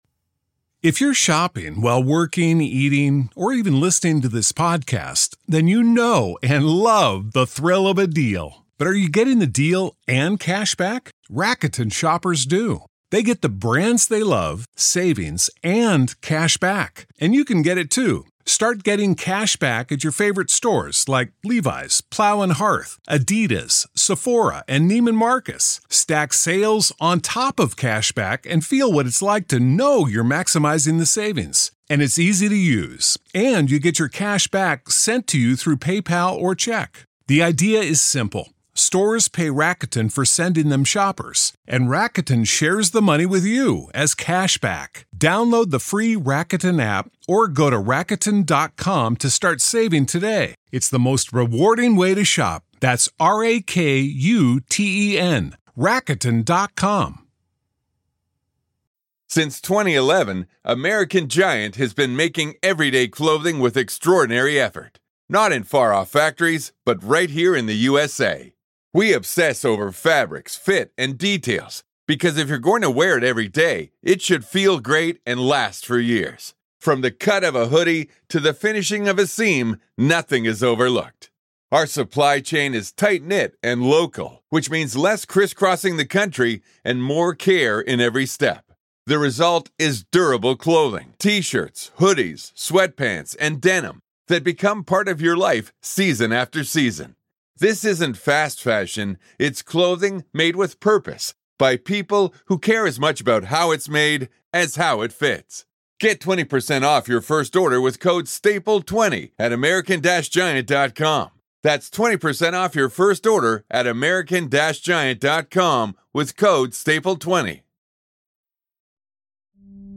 Powerful Motivational Speech is a focused and commanding motivational video created and edited by Daily Motivations. This powerful motivational speeches compilation reminds you that success is built through structure, not emotion.